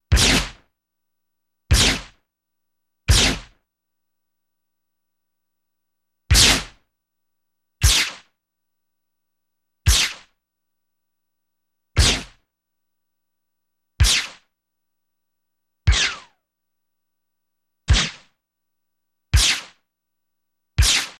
Generic Lasers
Laser Blasts Quick, x12